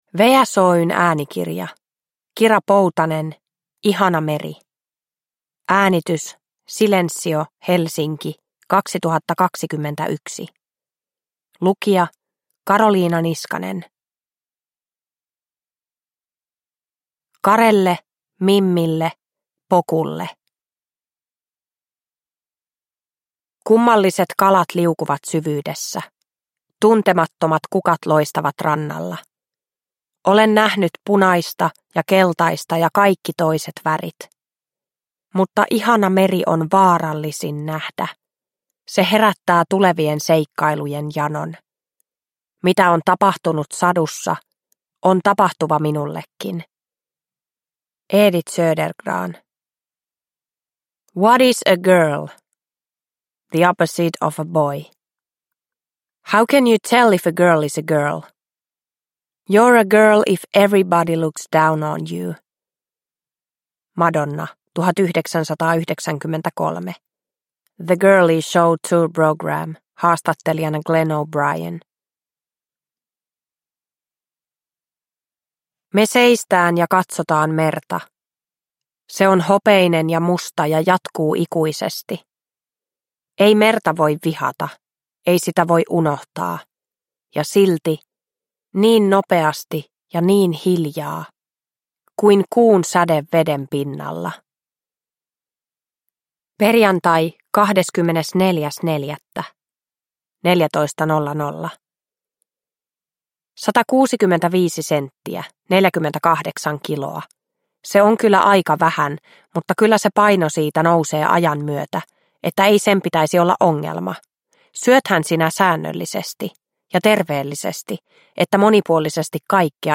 Ihana meri (ljudbok) av Kira Poutanen